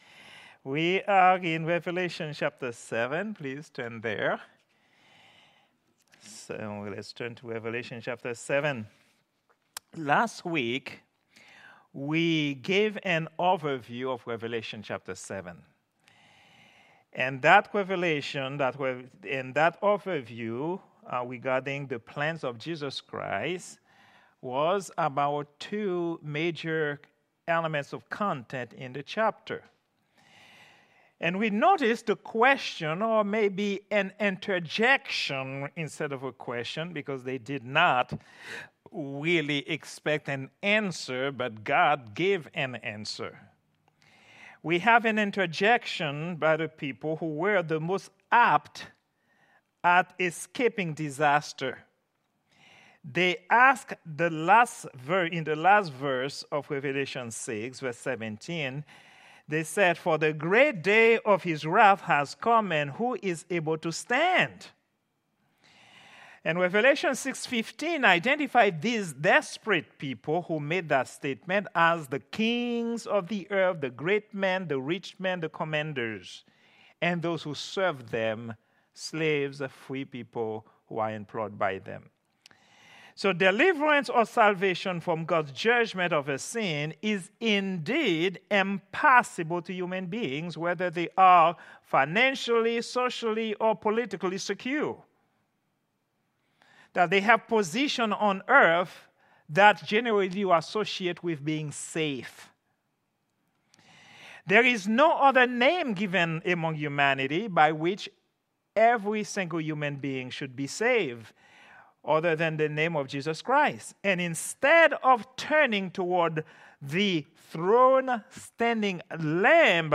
Prayer_Meeting_07_24_2024.mp3